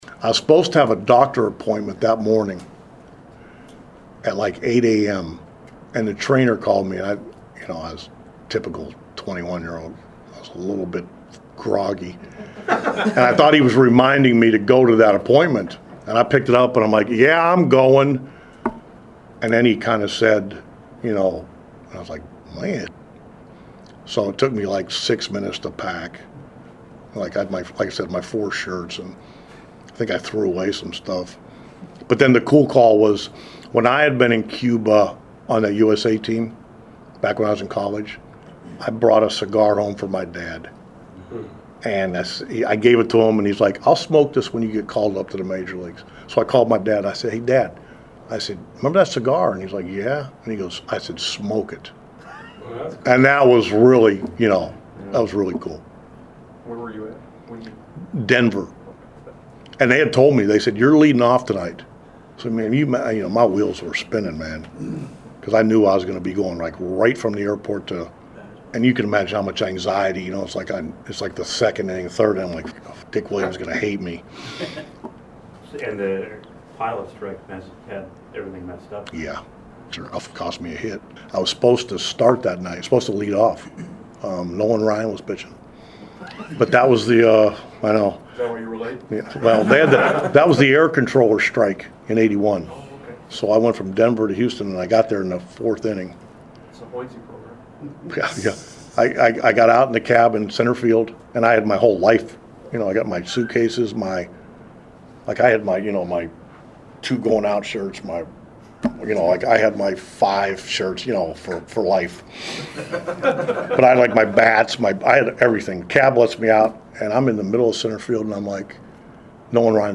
While Zimmer will forever have his story of his call-up, another former player now manager shared his story today with the media when he got called to the Majors – that being Tribe skipper Terry Francona.
Click the link below to hear Francona tell his story of his first MLB game.